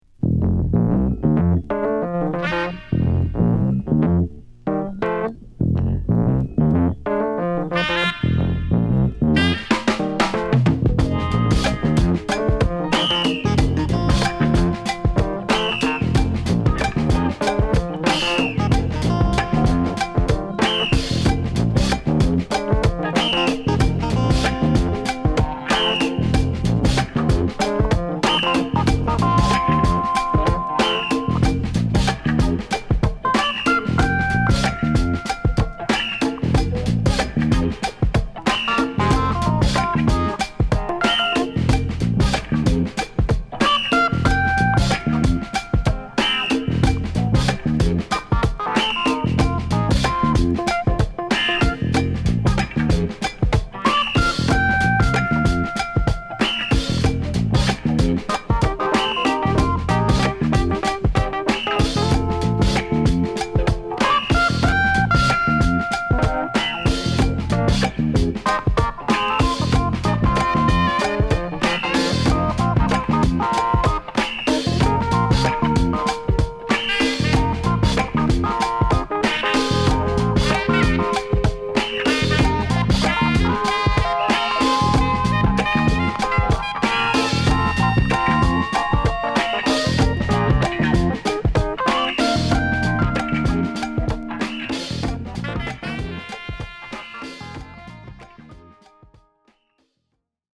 極太のベースにパーカッション／ホイッスルにクラヴィやピアノ／サックスが絡むコズミック・ファンクを披露！
(Stereo)